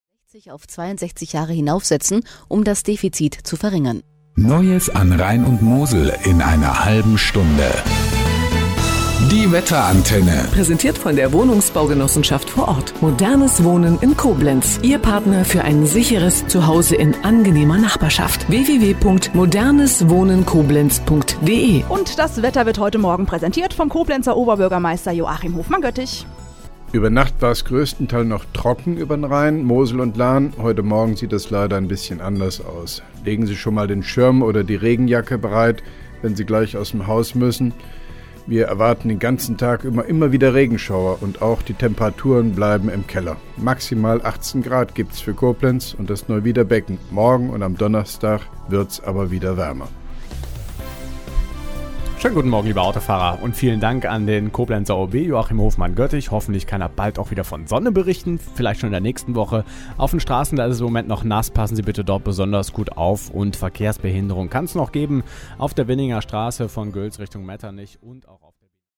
(4) Koblenzer OB Radio-Bürgersprechstunde 07.09.2010
• Verlesung des aktueller Koblenzer Wetterberichts vom Koblenzer OB Hofmann-Göttig